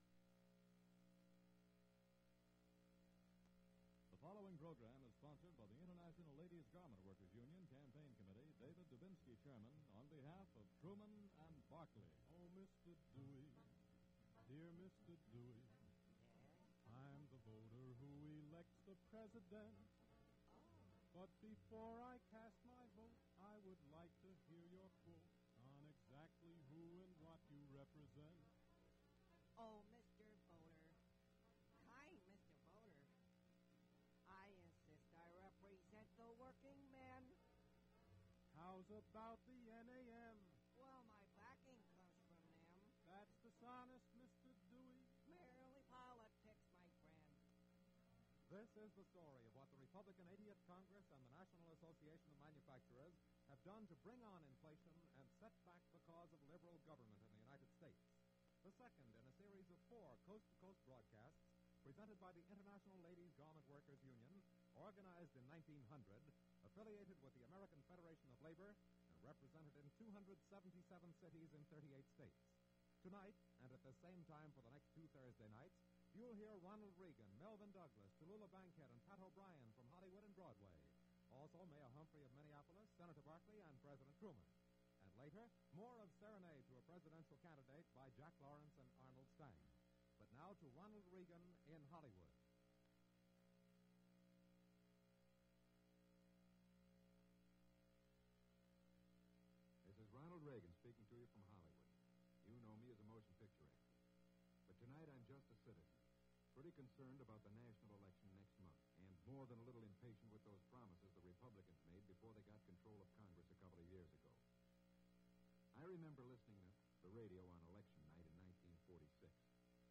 Ronald Reagan and Hubert Humphrey Spots for Truman-Barkley 1948 Presidential Campaign
Audio Cassette Format - 1948.